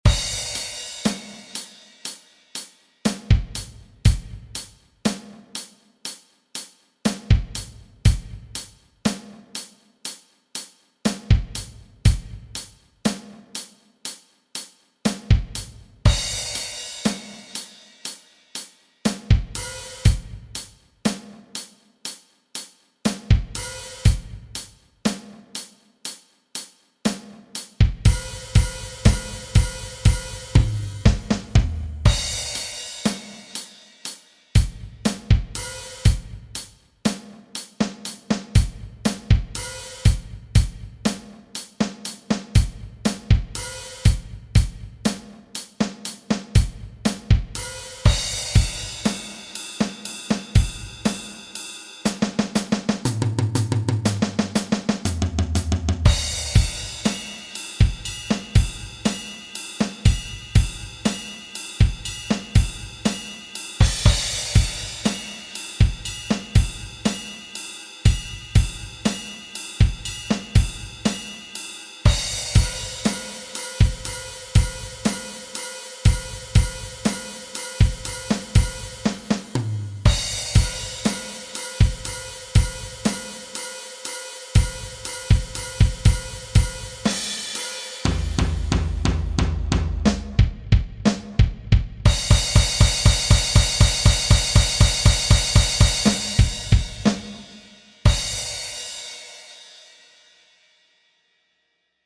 Die Noten und Hörbeispiele in schnell und langsam gibt es unten.
In 7 Schritten mehr Abwechslung langsam.mp3
in_7_schritten_mehr_abwechslung_langsam.mp3